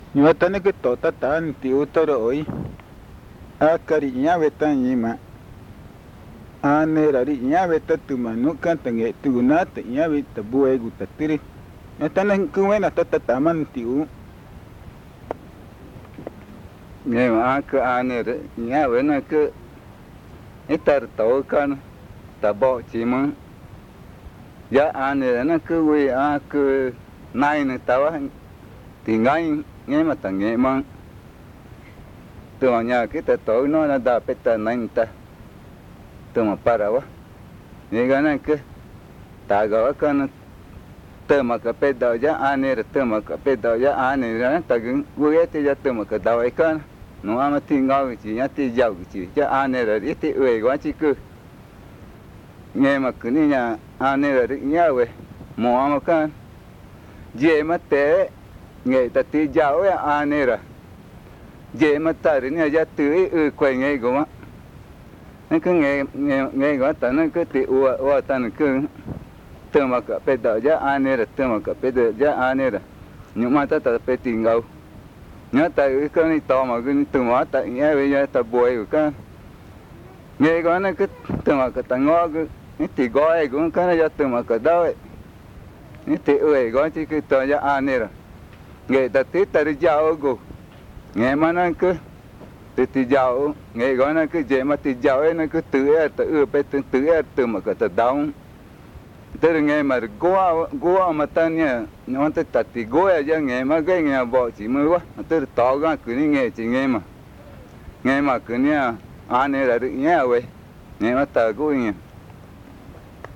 Arara, Amazonas (Colombia)